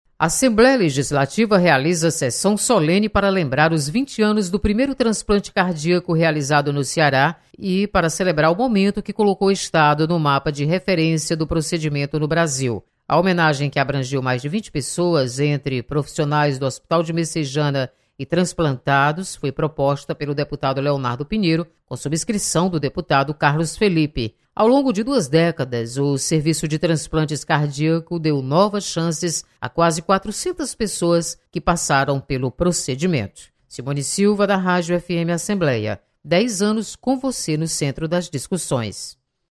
Sessão Solene homenageia 20 anos do primeiro transplante de coração no Ceará. Repórter